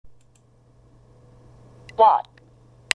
hはローマ字のハ行の文字に使われているため、whiteを「ホワイト」と言ったり、whale(くじら) を「ホエール」と言ったりしますが、ネイティブの言うwhにhは存在しないと言えるくらい弱く、疑問詞シリーズwhat、who、which、 when、where・・・の中でもhの音が生かされているのはwhoだけで、他はほとんど無視です。